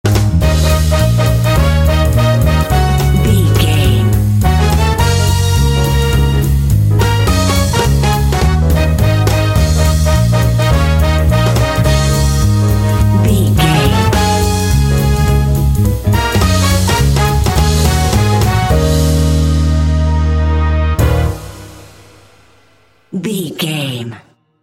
Aeolian/Minor
E♭
epic
energetic
groovy
lively
bass guitar
piano
drums
brass
jazz
big band